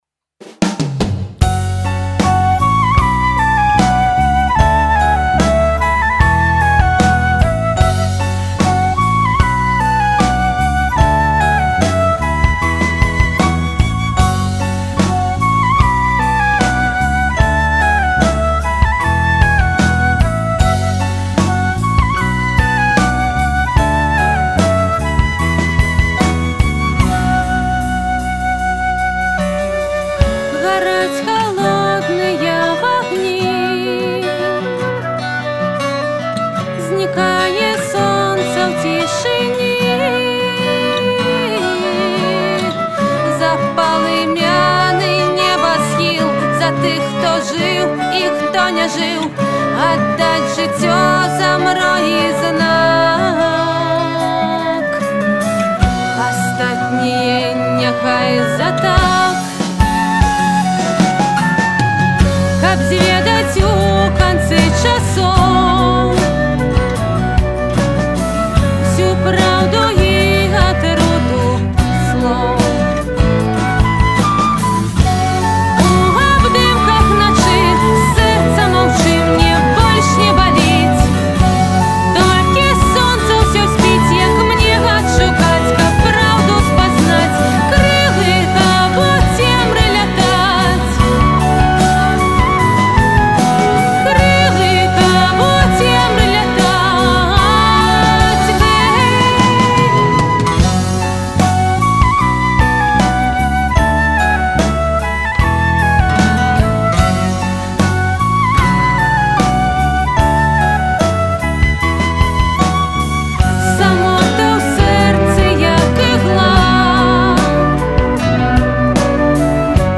Стыль - нэафольк, акустычны фольк.